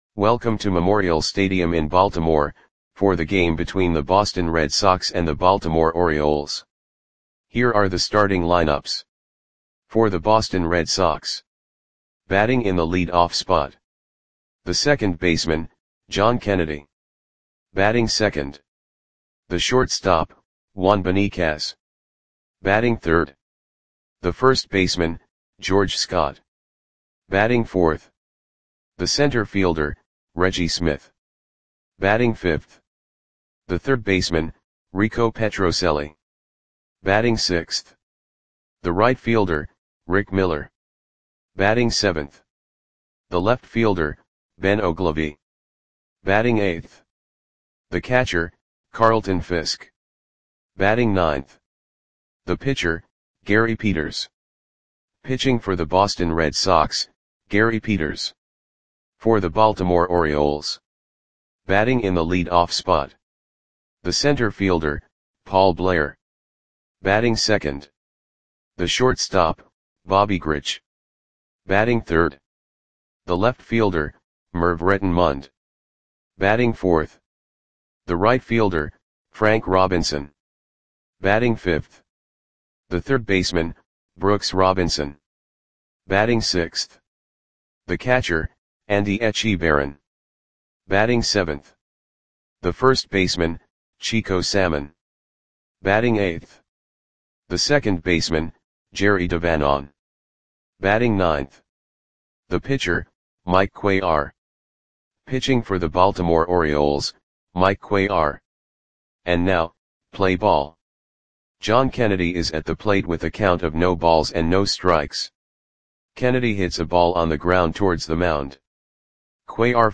Audio Play-by-Play for Baltimore Orioles on September 28, 1971
Click the button below to listen to the audio play-by-play.